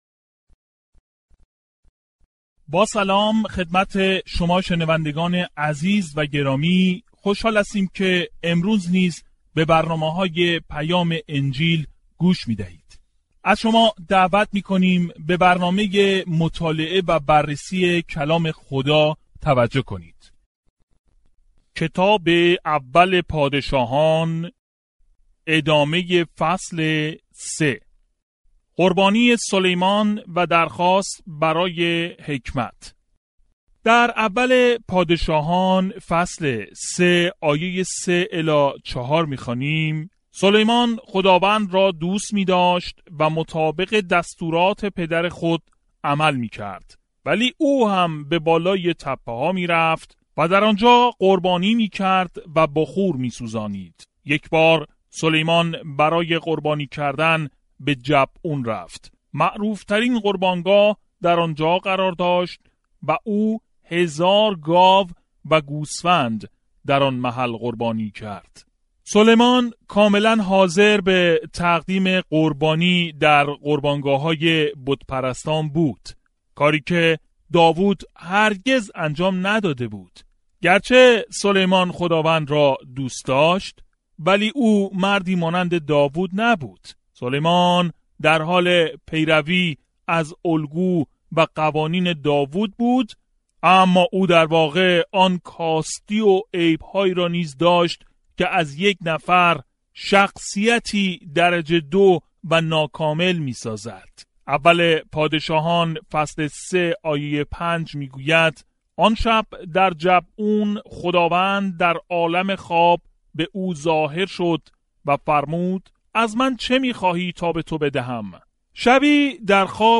کلام ۱پادشاهان 3:3-28 ۱پادشاهان 1:4-21 روز 3 شروع این برنامه مطالعه روز 5 دربارۀ اين برنامۀ مطالعه کتاب پادشاهان داستان چگونگی شکوفایی پادشاهی اسرائیل در دوران داوود و سلیمان را ادامه می دهد، اما در نهایت پراکنده شد. با گوش دادن به مطالعه صوتی و خواندن آیات منتخب از کلام خدا، روزانه در 1 اول پادشاهان سفر کنید.